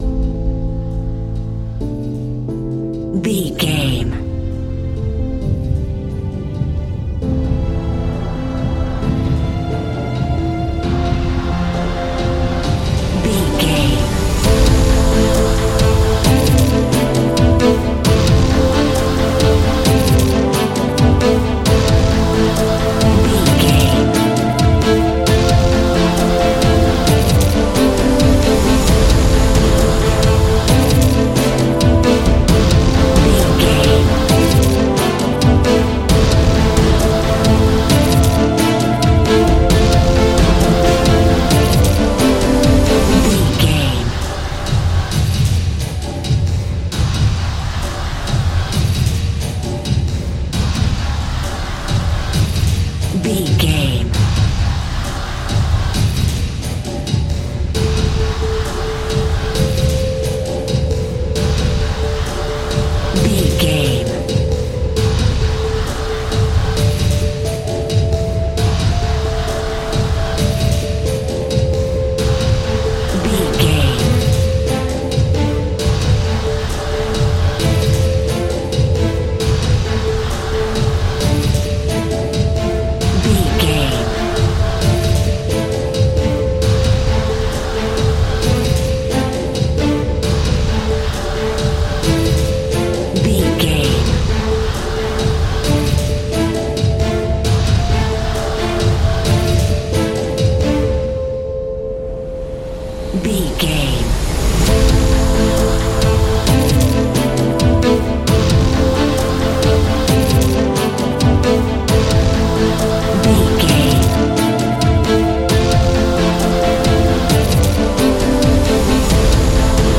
Aeolian/Minor
angry
electric guitar
drums
bass guitar